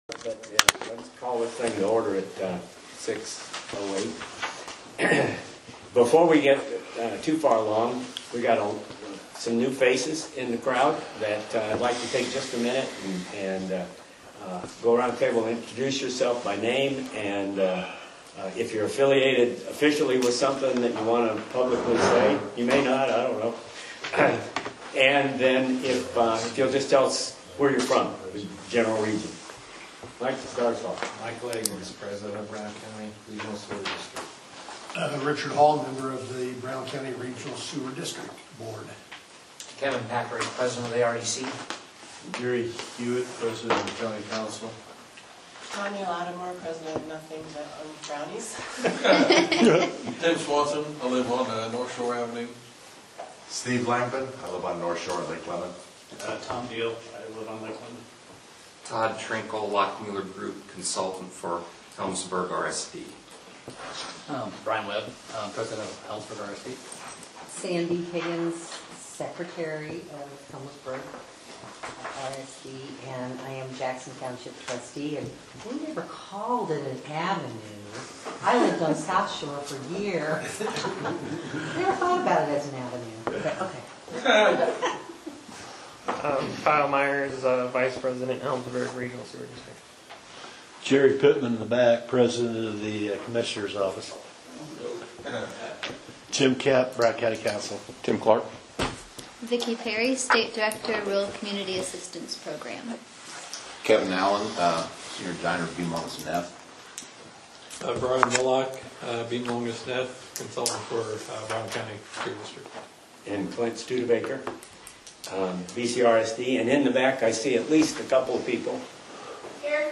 In addition to the Helmsburg and Brown County RSD boards, the meeting was attended by the President of the commissioners’ Jerry Pittman, President of the Council Gary Huett, Councilman Jim Kemp, and a member of the Gnawbone RSD Board. Attendees also included contractors, several people from the Lake Lemon area that support the expansion of sewers in their community, and at least one person from Bean Blossom and Helmsburg.